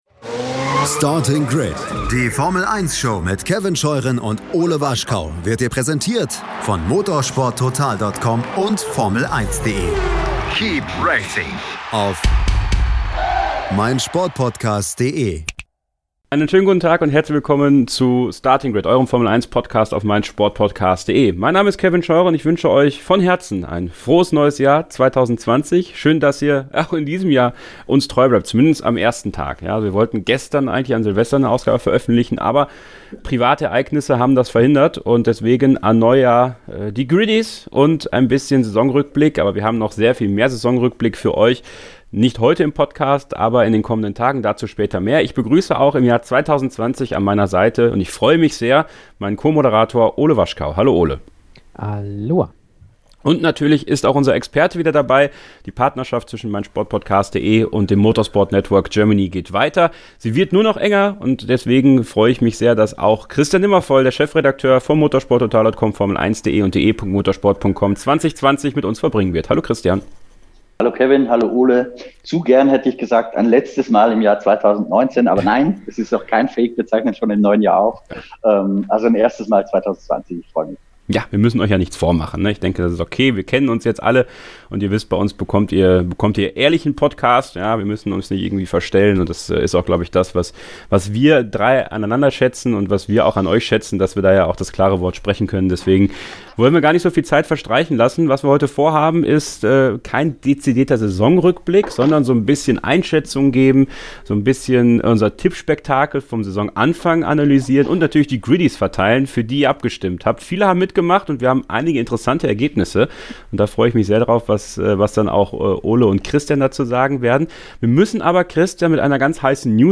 Etwas später als geplant geht heute unsere Award-Show online.